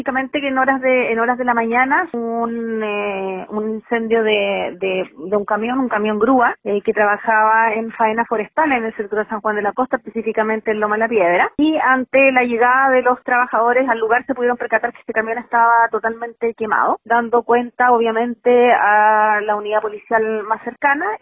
fiscal-2.mp3